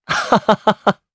Esker-Vox_Happy3_jp_c.wav